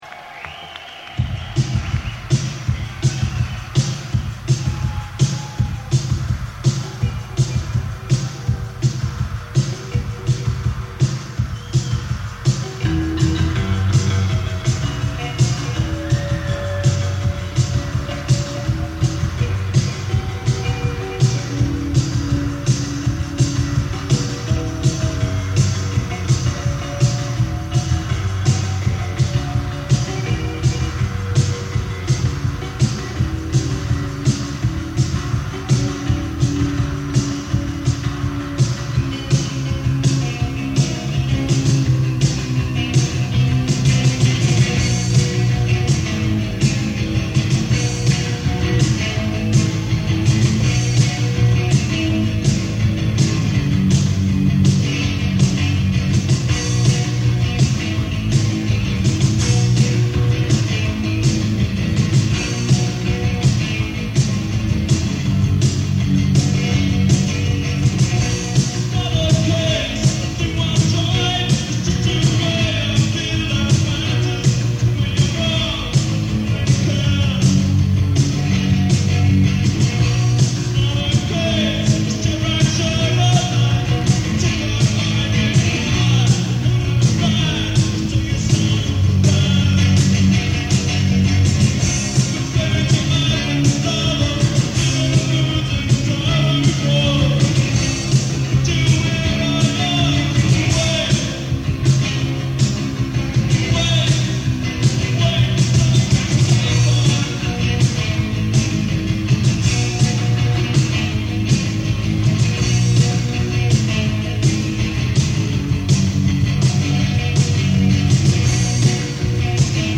Playlist « Live 80’s : j’étais dans la salle ! »
Strasbourg, Hall Tivoli